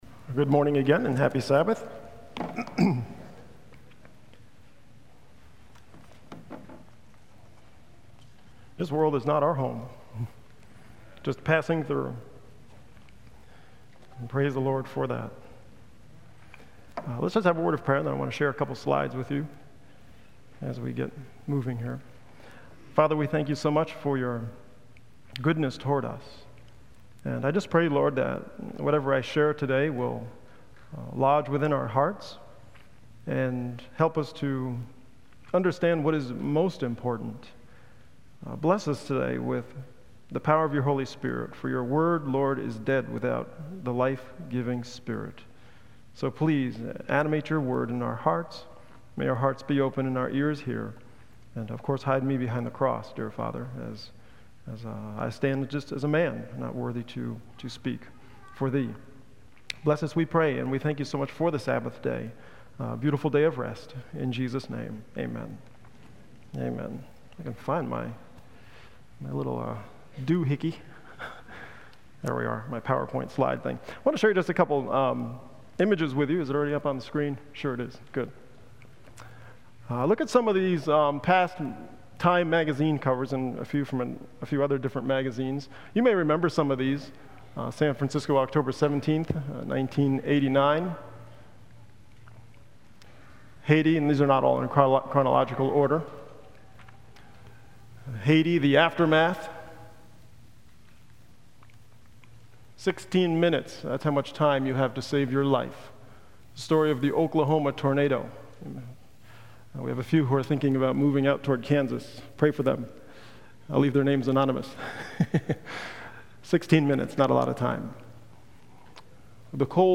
on 2015-07-03 - Sabbath Sermons